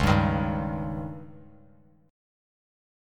C#sus2b5 chord